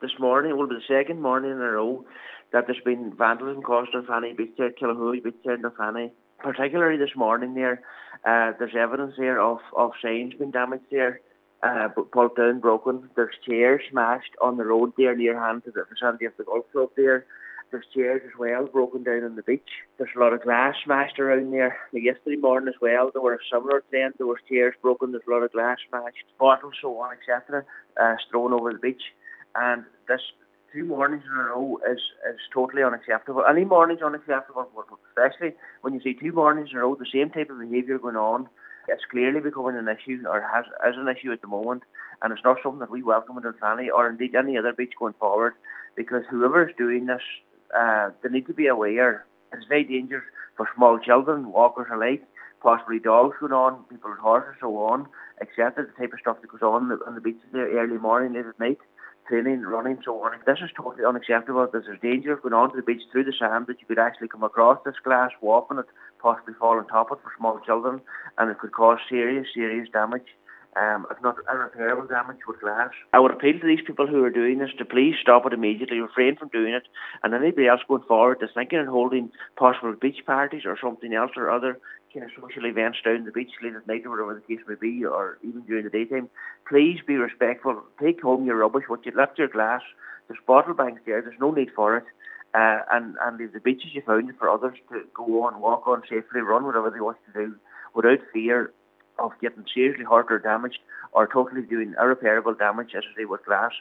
Cllr McClafferty has appealed to those responsible to be respectful of the area and stop: